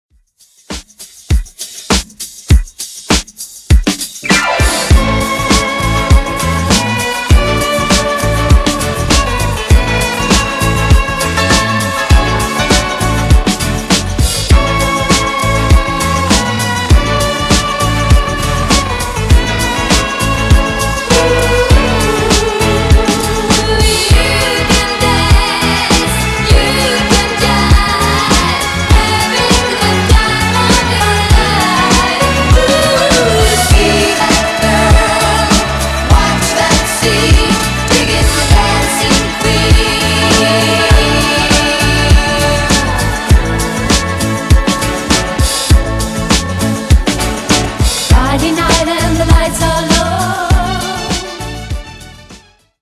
Genres: RE-DRUM , TIK TOK HITZ Version: Clean BPM: 101 Time